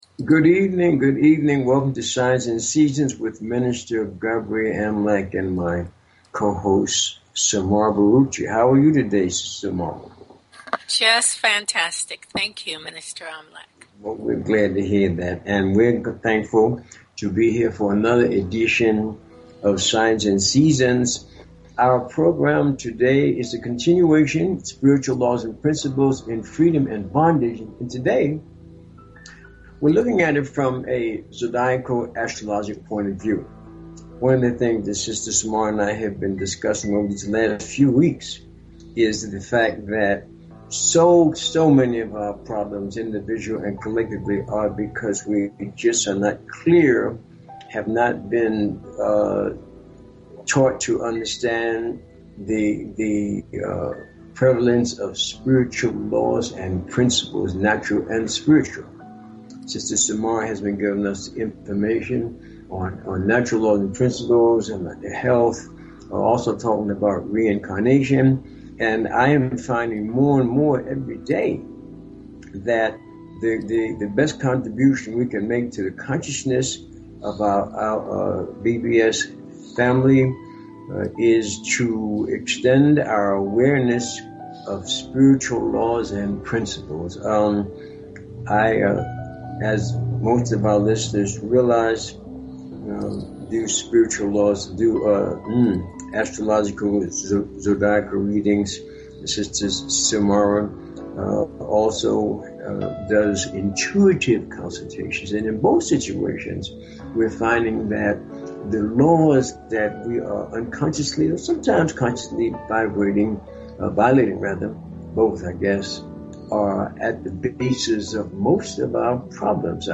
Talk Show Episode, Audio Podcast, Signs_and_Seasons and Courtesy of BBS Radio on , show guests , about , categorized as
The Primary objectives of this broadcast are: To ELIMINATE the fearful and superstitious attitudes that many peple have towards Astrology and related "Metaphysical" teachings by providing information on the constructive uses of these ancient sciences. And to demonstrate the practical value of Astrology and Numerology by giving on air callers FREE mini readings of their personal Astro-numerica energy profiles.